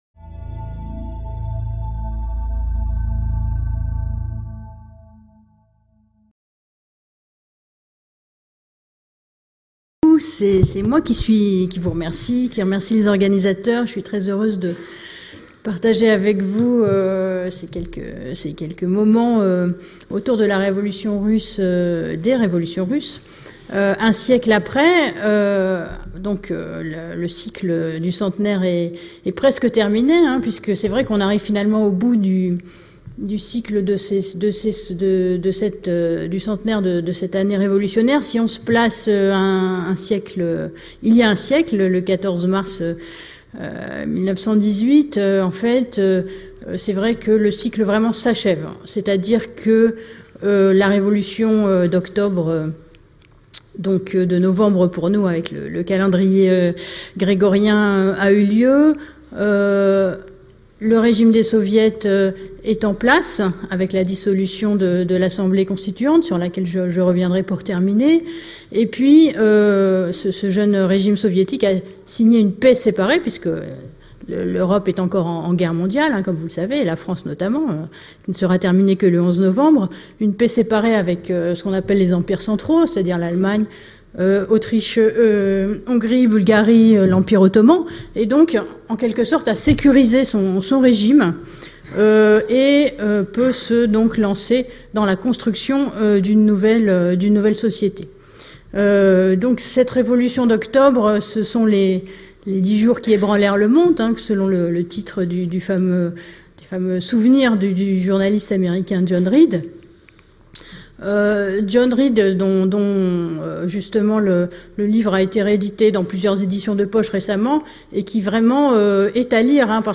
La conférence reviendra sur l’année 1917 marquée en Russie par les deux révolutions de Février et Octobre.